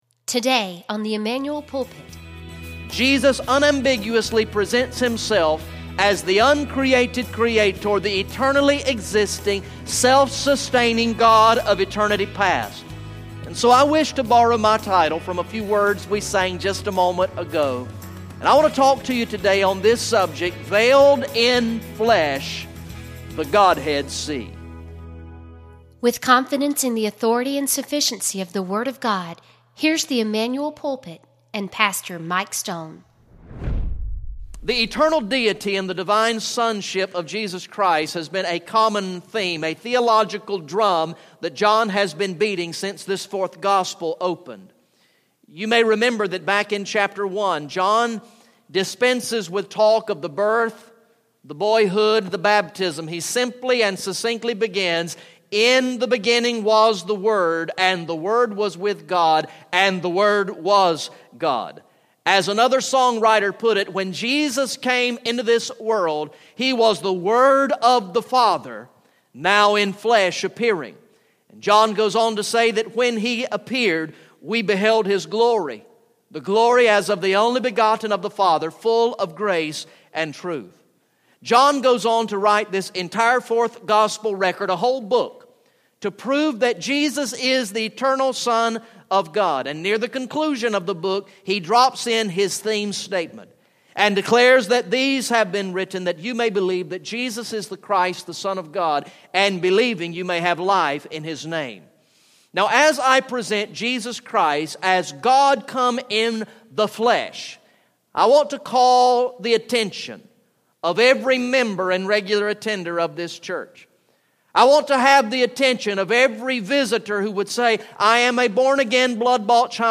Message #51 from the sermon series through the gospel of John entitled "I Believe" Recorded in the morning worship service on Sunday, December 20, 2015